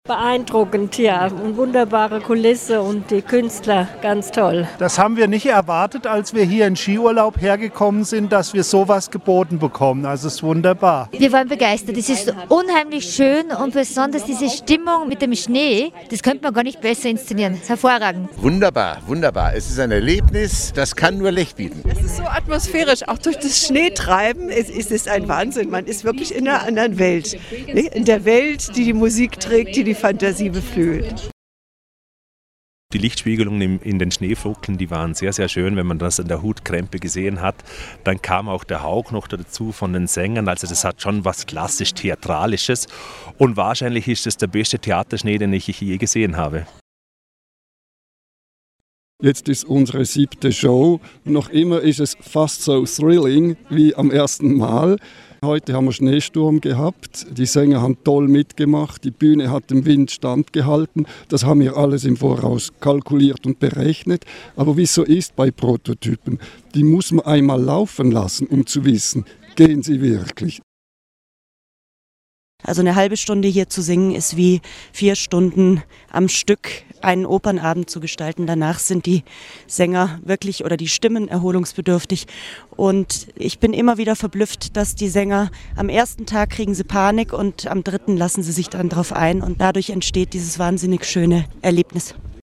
Stimmen zu Spiel auf dem Schnee